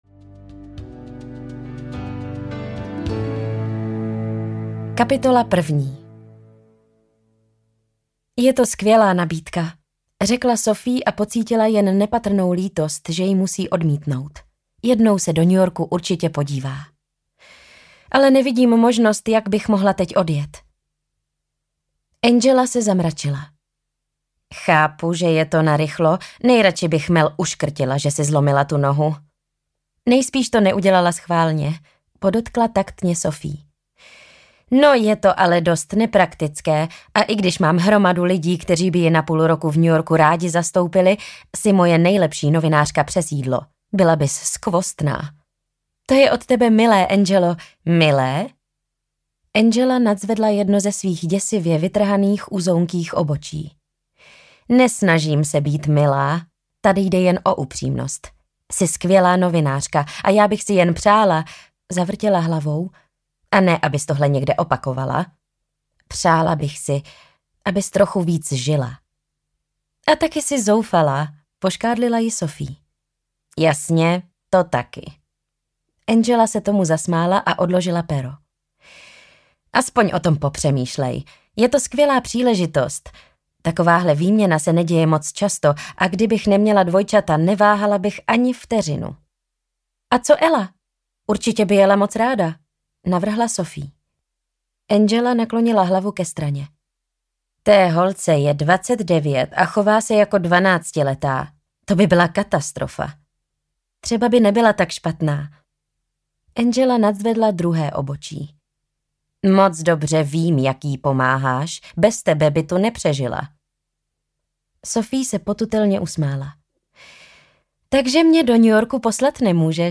Pekárna v Brooklynu audiokniha
Ukázka z knihy